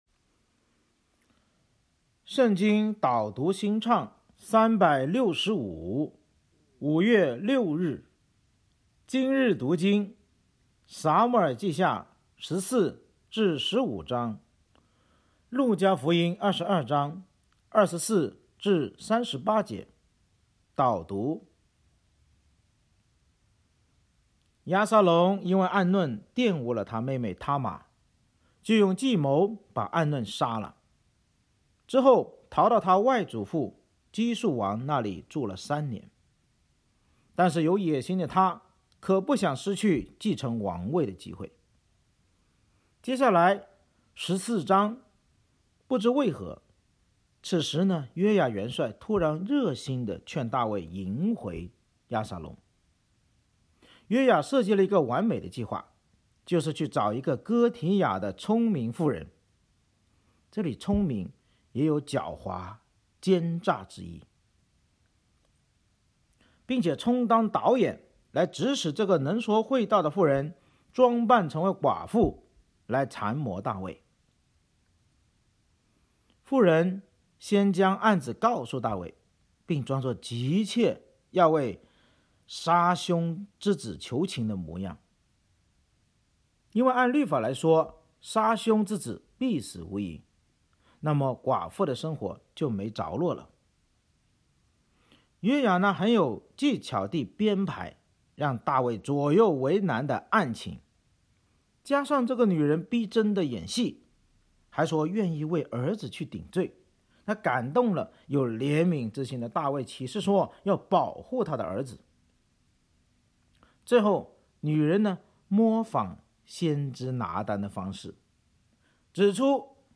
【导读新唱365】朗读5月6日.mp3